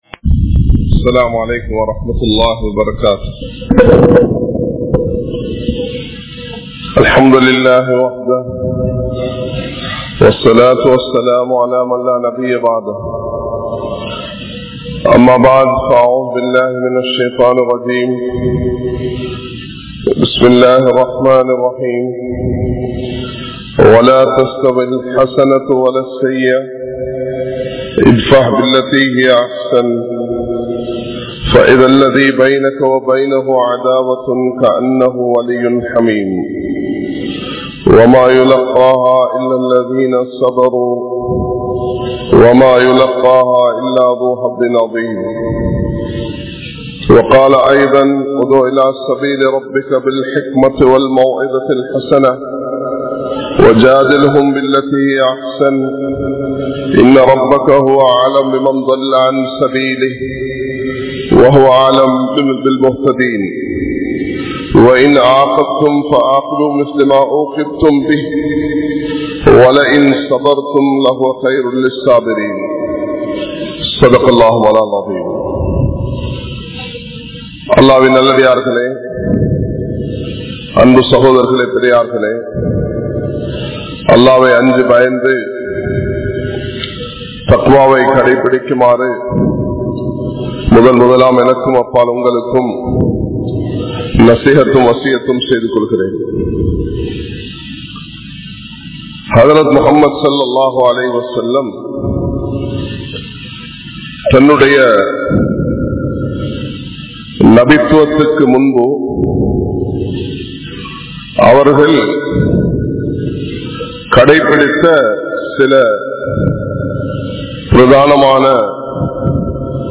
Muslimkale! Nithaanaththai Ilanthu Vidaatheerhal (முஸ்லிம்களே! நிதானத்தை இழந்து விடாதீர்கள்) | Audio Bayans | All Ceylon Muslim Youth Community | Addalaichenai
Dehiwela, Muhideen (Markaz) Jumua Masjith